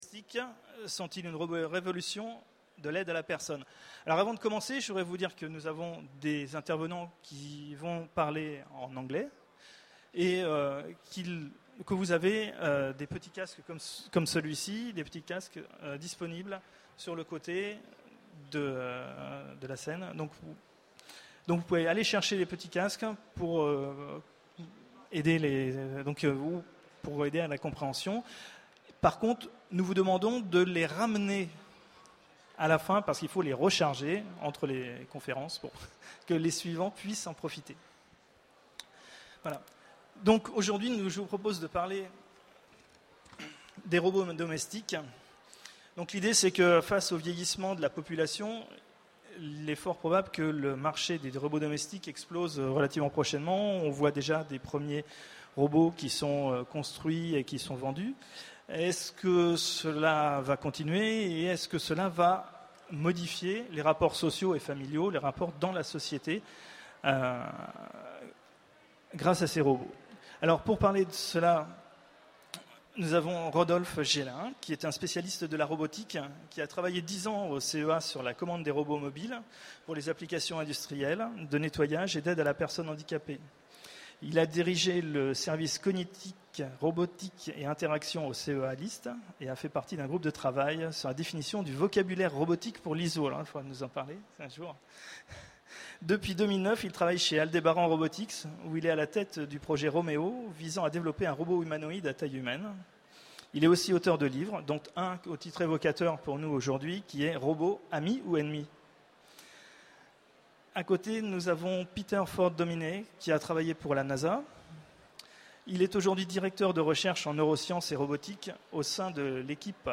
Utopiales 12 : Conférence Les robots domestiques
Conférence